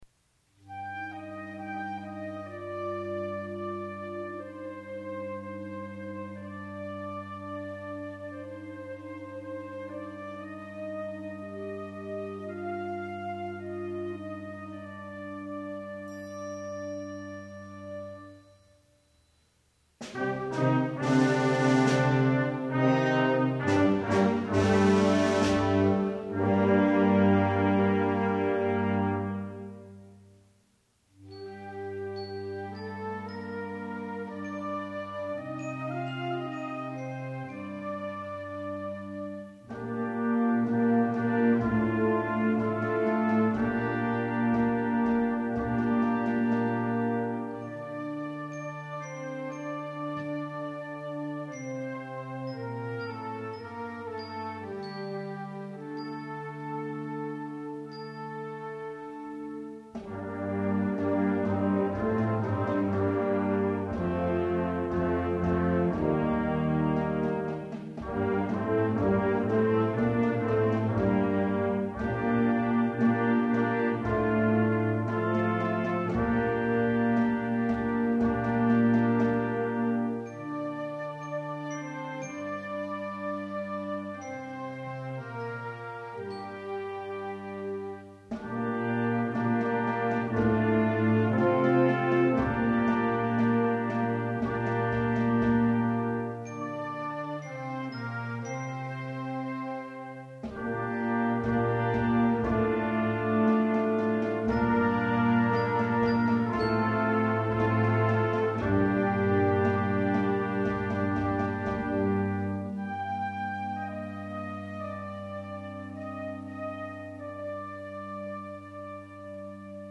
Genre: Band
Flute
Oboe
Bb Clarinet 1/2
Alto Saxophone
Bb Trumpet 1/2
Tuba
Percussion [2 players] (triangle, snare drum, bass drum)
Bells